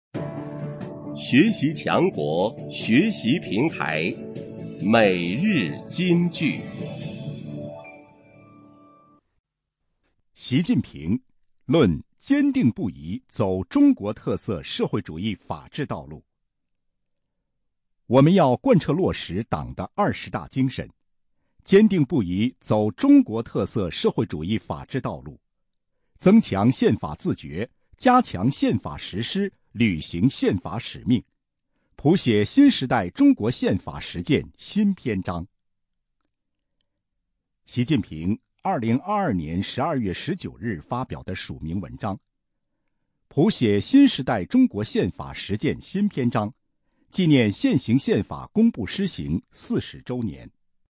每日金句（朗读版）|习近平论坚定不移走中国特色社会主义法治道路 _ 学习宣传 _ 福建省民政厅